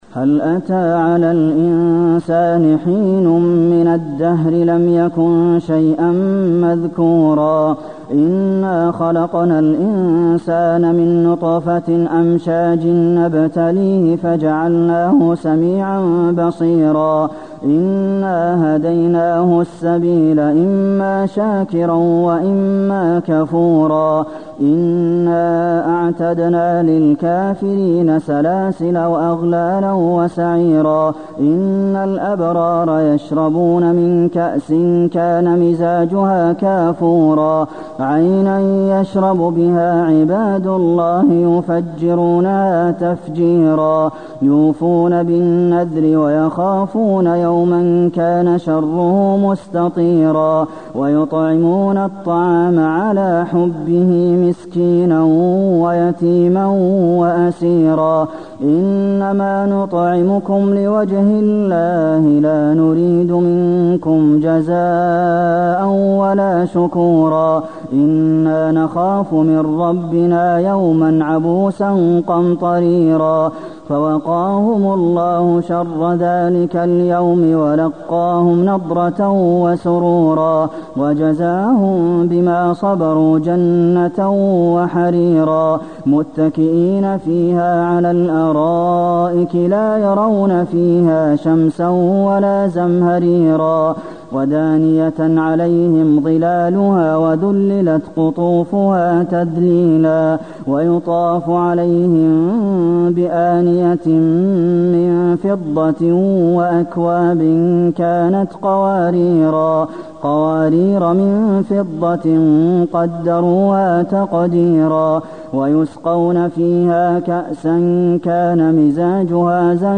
المكان: المسجد النبوي الإنسان